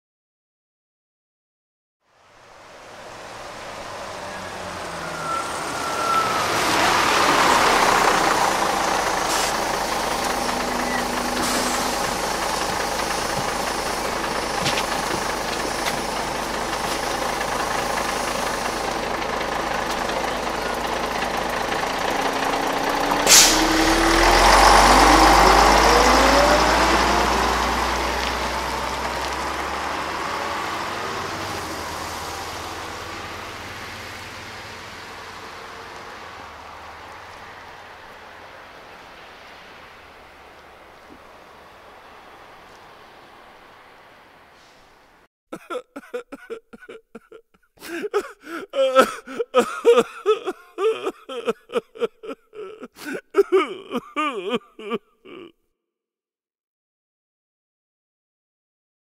دانلود صدای ایستادن اتوبوس در ایستگاه از ساعد نیوز با لینک مستقیم و کیفیت بالا
جلوه های صوتی